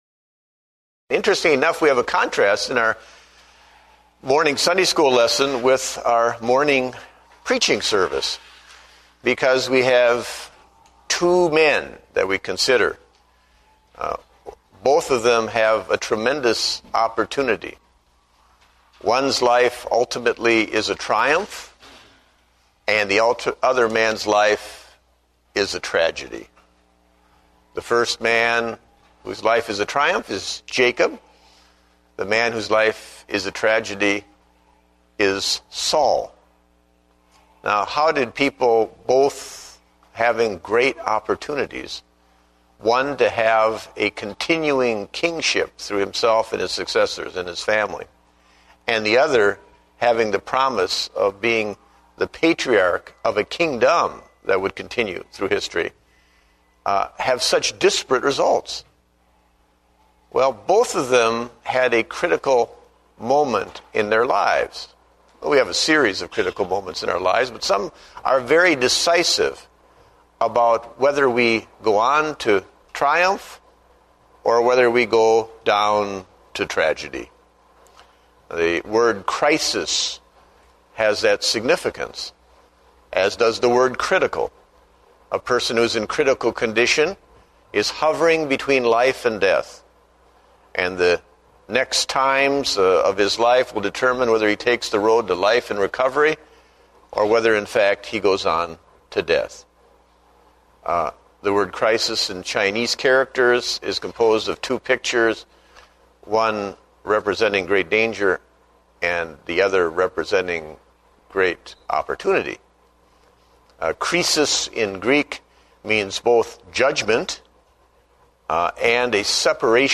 Date: April 26, 2009 (Adult Sunday School)